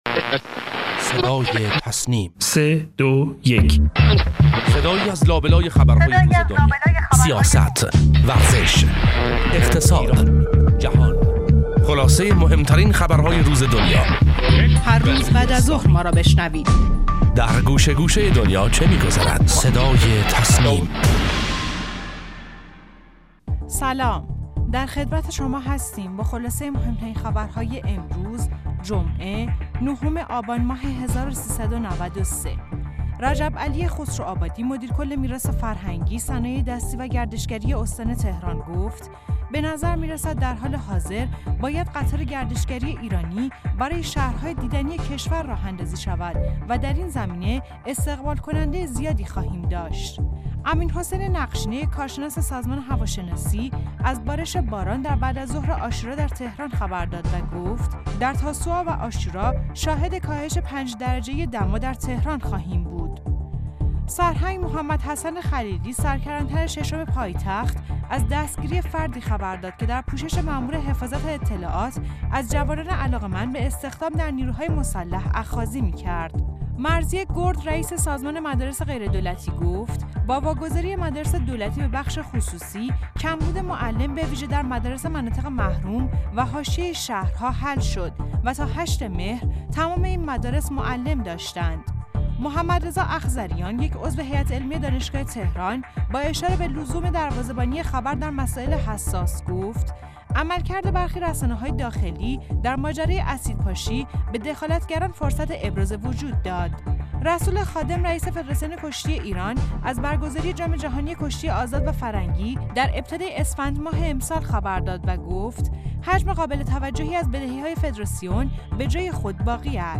خبرگزاری تسنیم: مهمترین اخبار و گزارشات درباره موضوعات داخلی و خارجی امروز را از «صدای تسنیم» بشنوید.